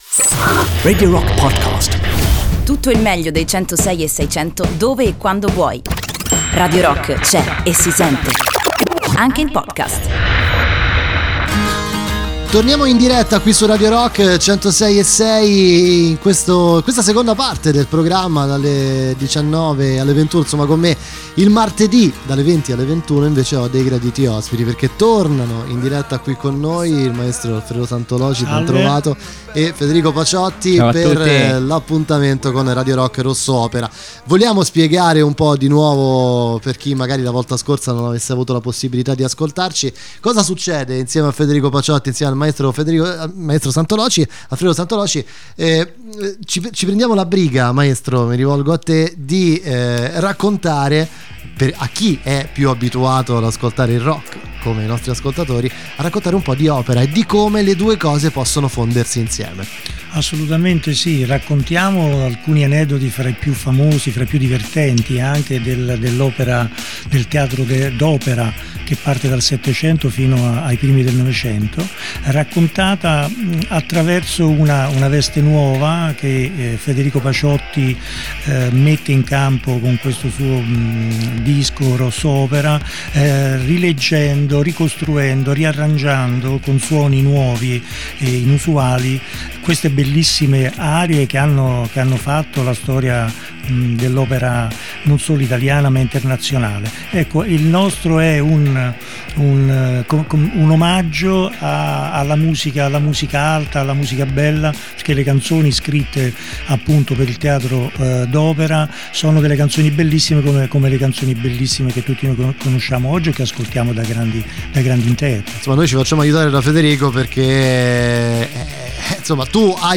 In diretta dalla SALA LIVE di Radio Rock
Appuntamento a martedì alternati (a partire dal 3 Aprile 2018) dalle ore 20.00 alle 21.00. Collegamento telefonico con Steven Mercurio.